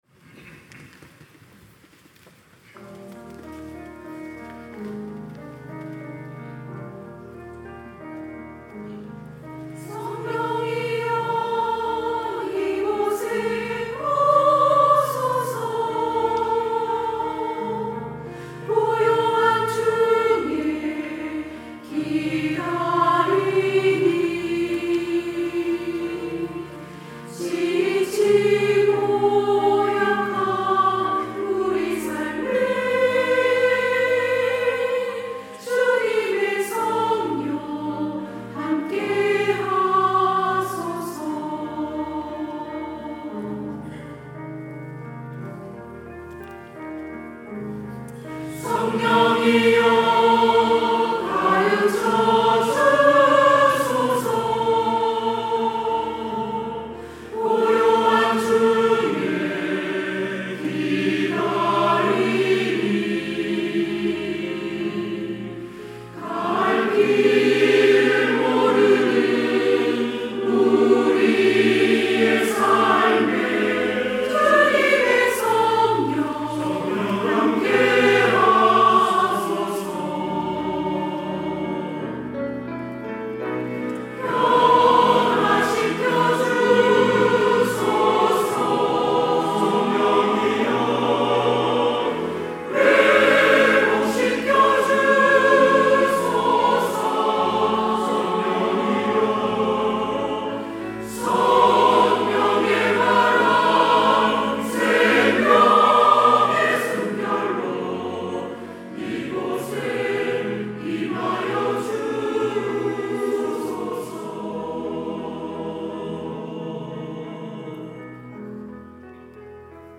시온(주일1부) - 성령이여, 이곳에 오소서
찬양대